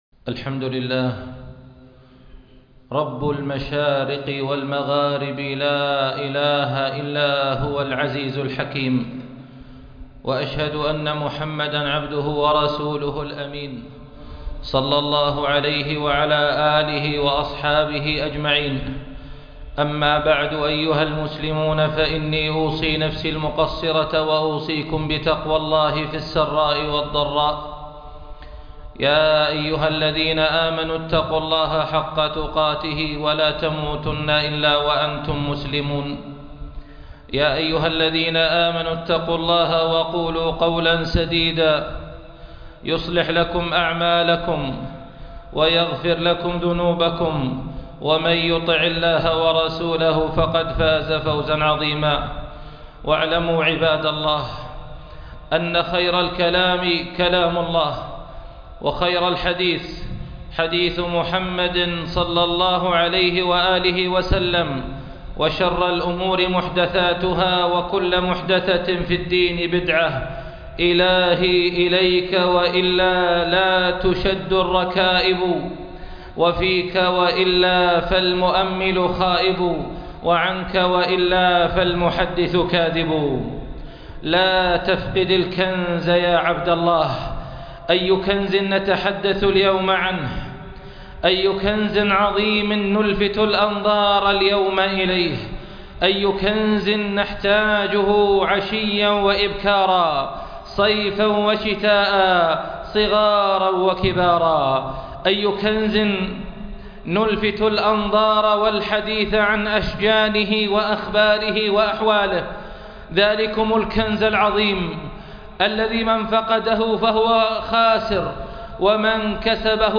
لا تفقد الكنز ( خطب الجمعة